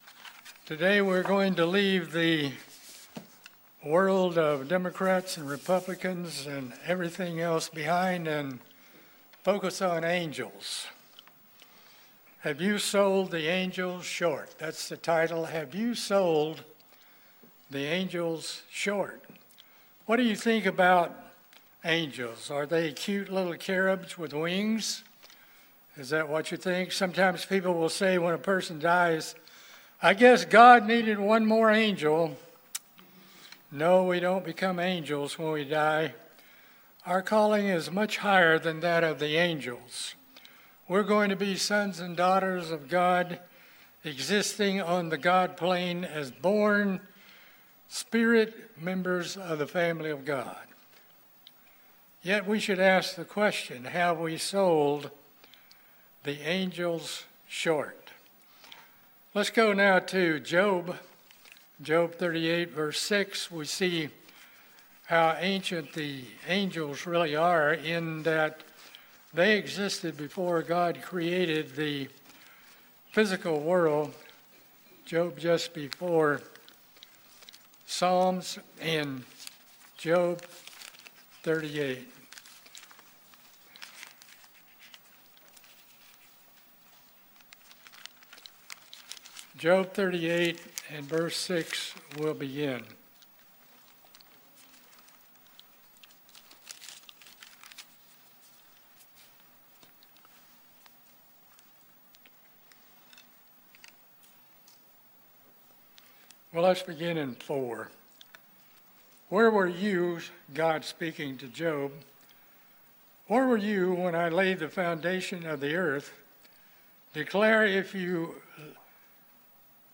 This sermon explorers the origin of angels and their principal duties. Along with other duties, we might not be aware of -- including the preaching, of the everlasting gospel to the world as noted in Revelation 14 and the three angel's messages.